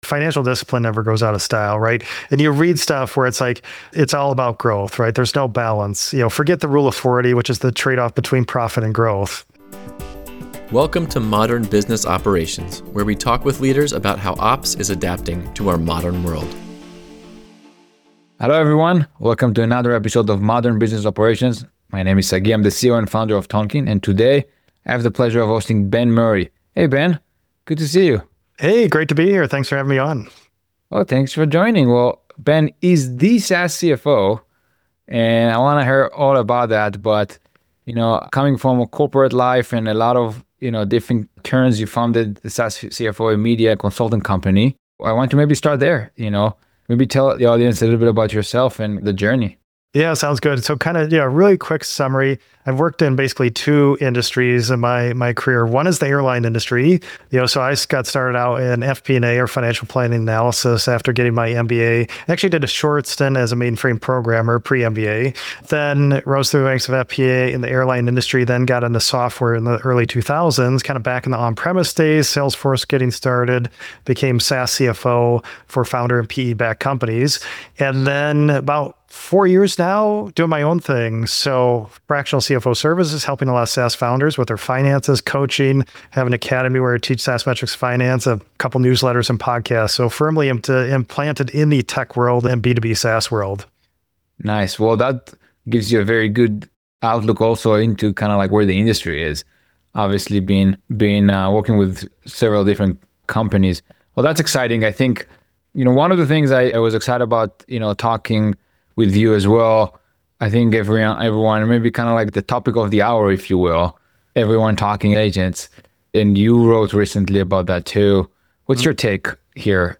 Play Rate Listened List Bookmark Get this podcast via API From The Podcast On Modern Business Operations, we’ll bring you weekly interviews with leaders from some of today’s largest and fastest-growing companies. We discuss the future of operations within business, including finance, revenue, legal, and HR.